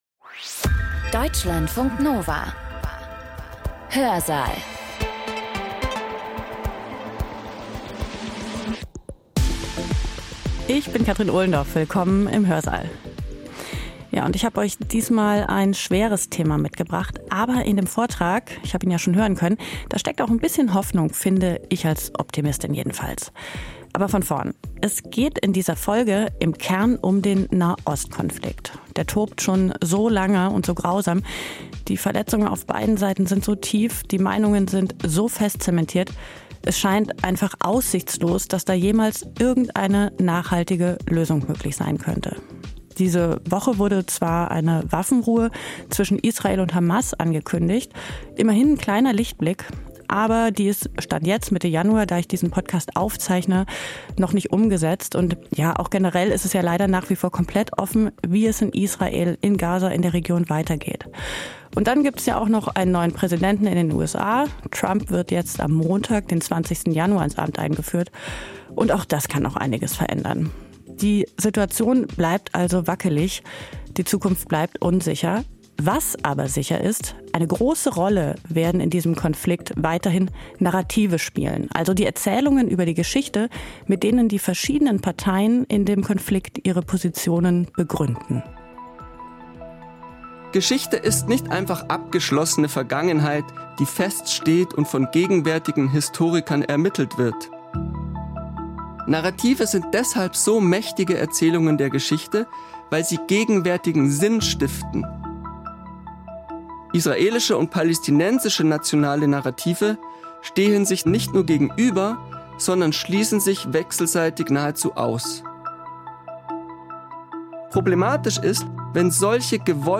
Ein Vortrag